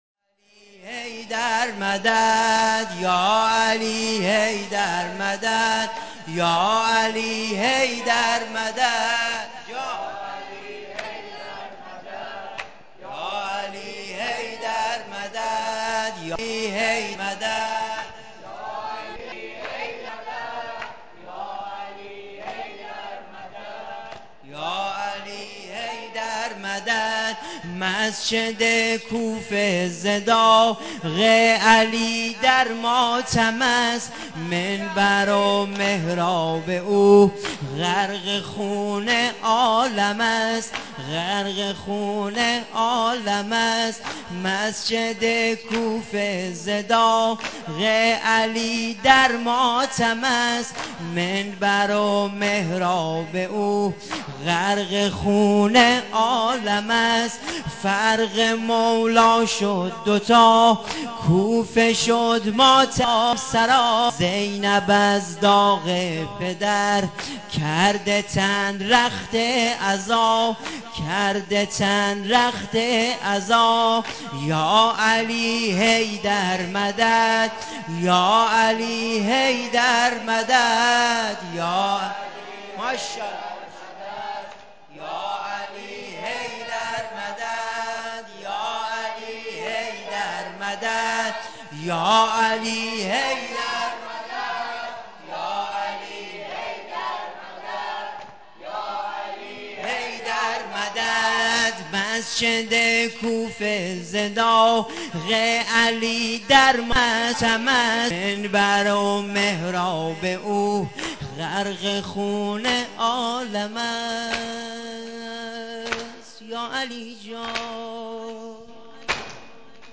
نوحه سینه زنی(شهادت امام علی
رمضان ۹۷
شب قدر